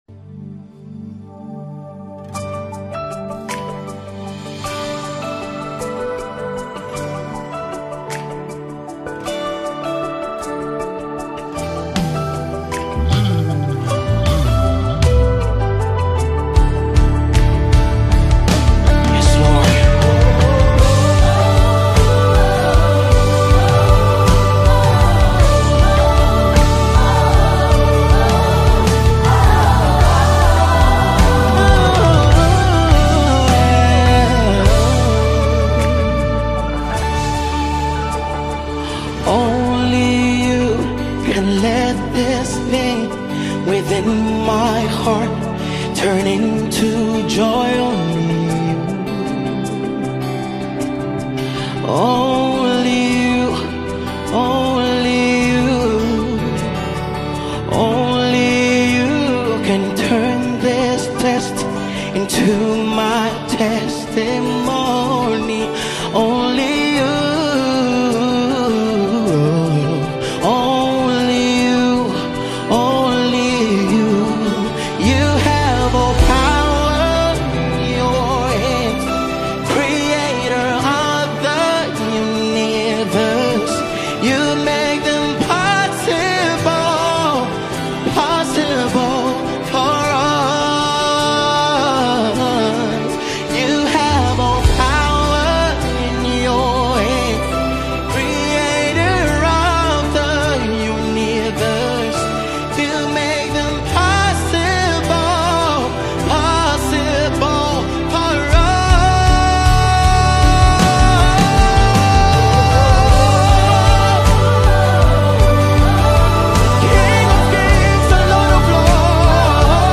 Ghana’s renowned contemporary gospel collective
deeply moving worship song